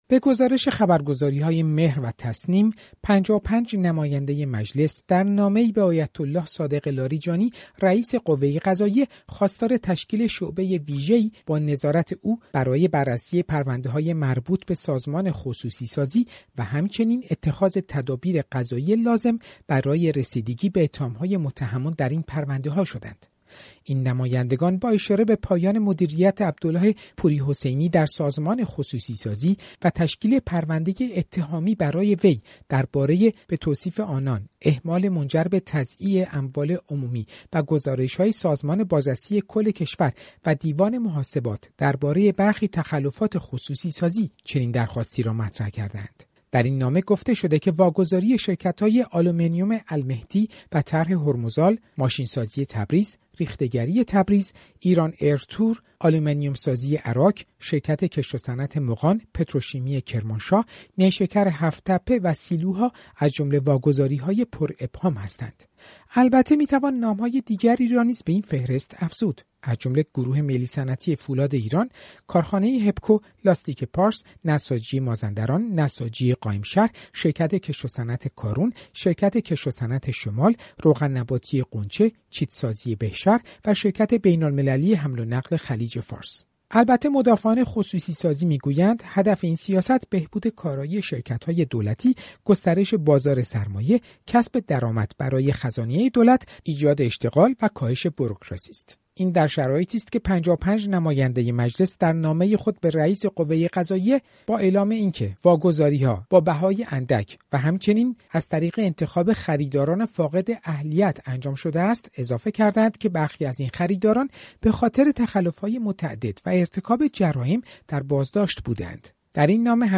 گزارشی در این زمینه بشنوید: